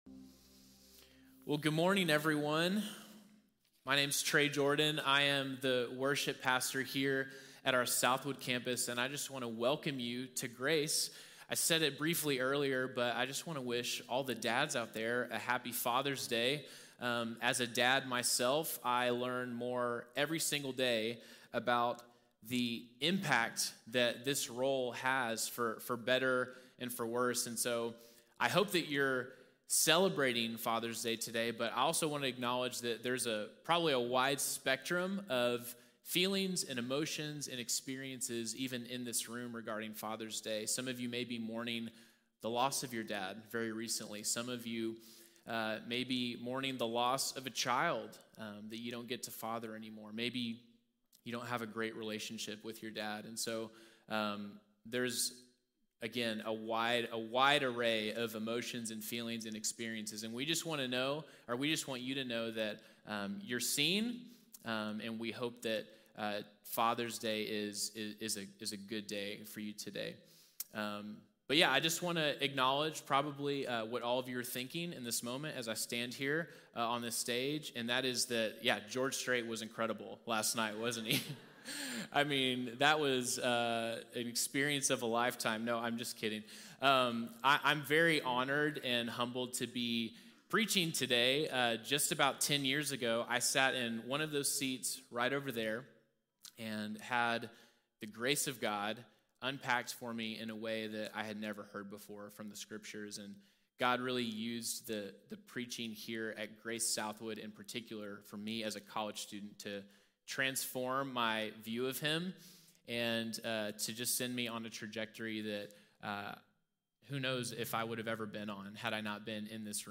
Empty Worship | Sermon | Grace Bible Church